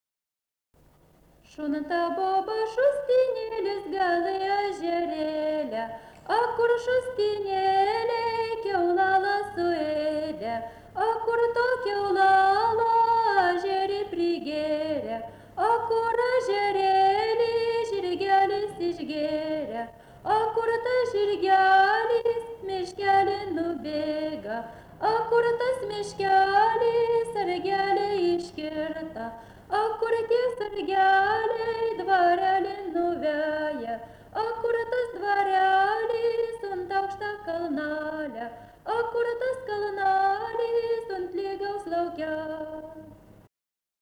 Dalykas, tema daina
Erdvinė aprėptis Juodšiliai Vilnius
Atlikimo pubūdis vokalinis